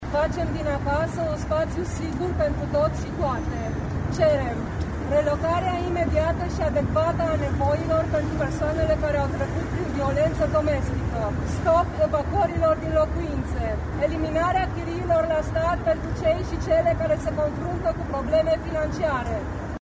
Participantele la protest și-au citit răspicat revendicările:
Revendicari-ambianta.mp3